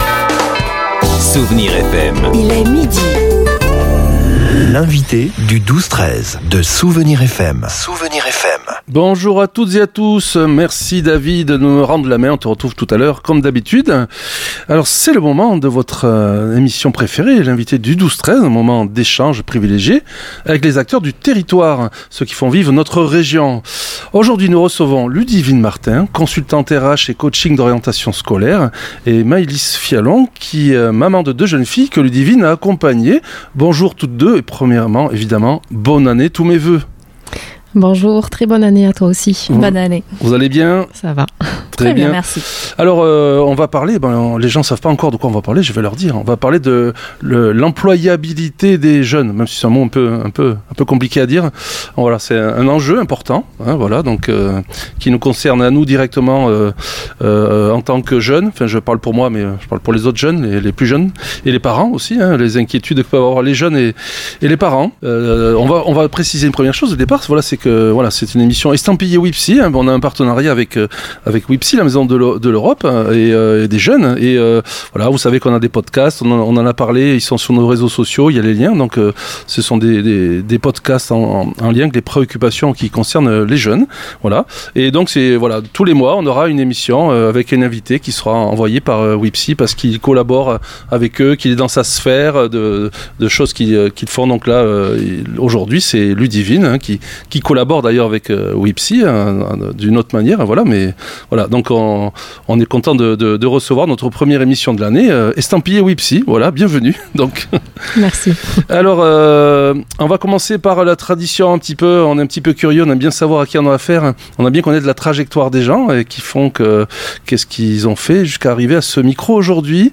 Nos invitées nous ont éclairés sur ce passage parfois délicat entre les études et le premier emploi, un moment où les jeunes se sentent souvent perdus face aux attentes du monde du travail.